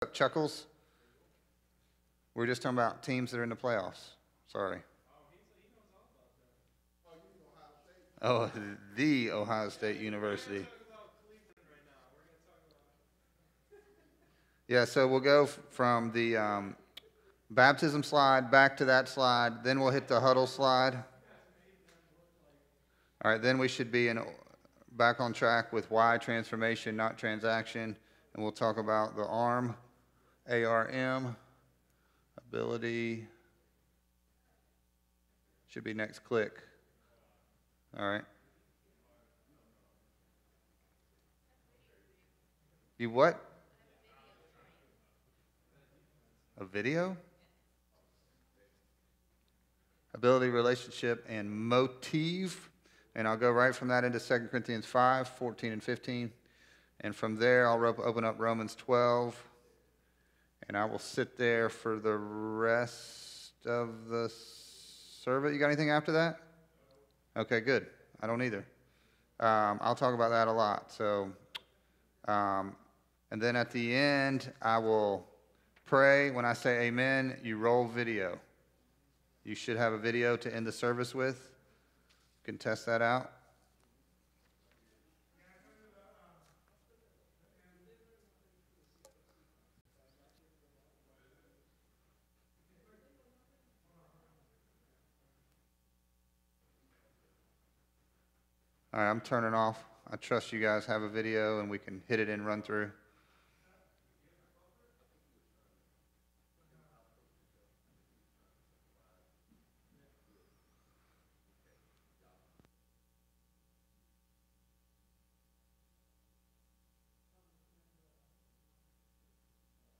A message from the series "Progress."